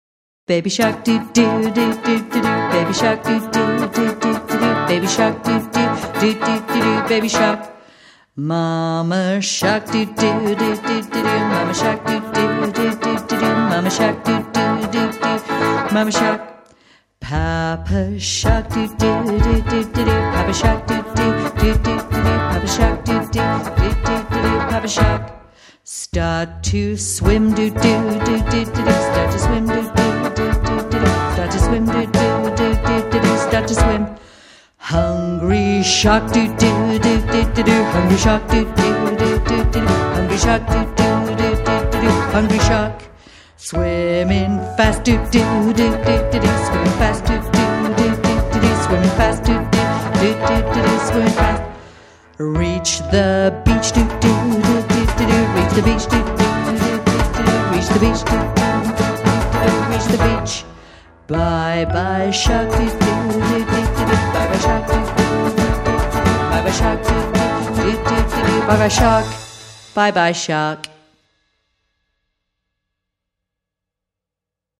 The English theatre company IPA productions came to school last week to perform the theatre play “The Sea”.
The boys and girls participated a lot singing and dancing.